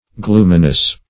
Gloominess \Gloom"i*ness\, n.